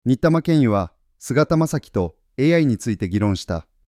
専門用語や固有名詞が正しく発音されない場合は、入力方法を工夫しましょう。